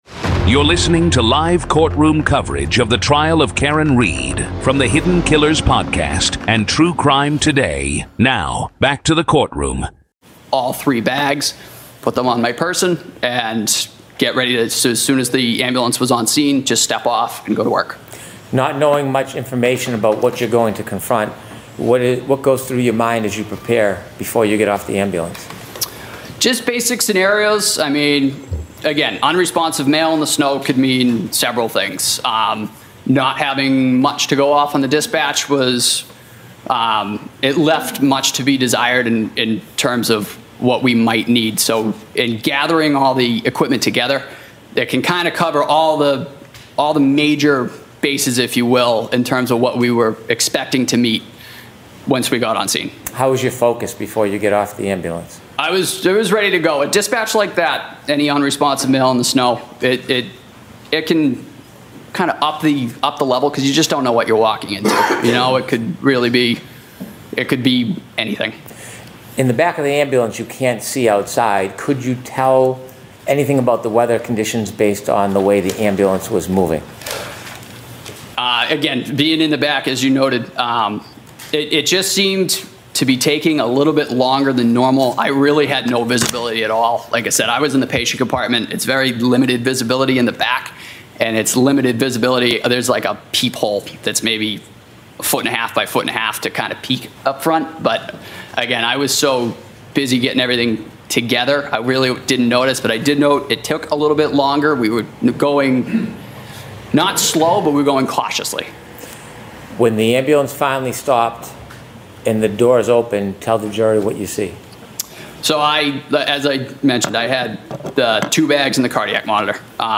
This is live audio from the courtroom in the high-profile murder retrial of Karen Read in Dedham, Massachusetts.